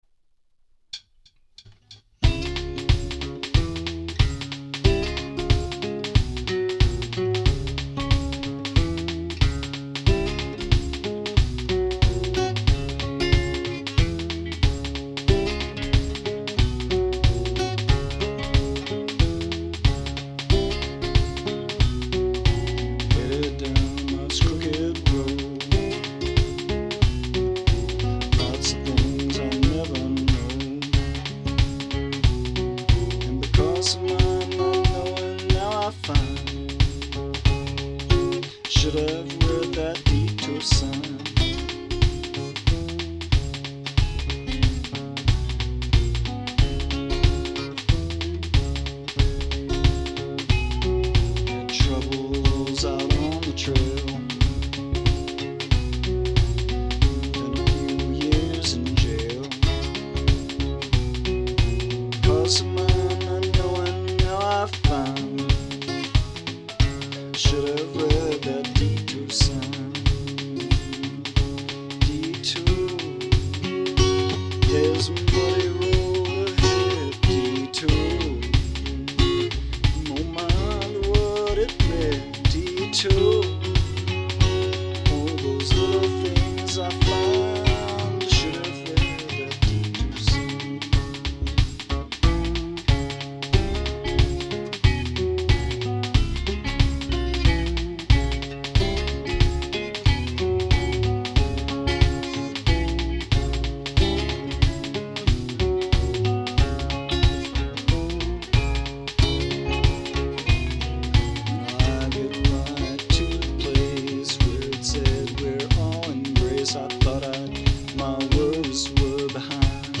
guitar, mandolin, vocals, keyboards, percussion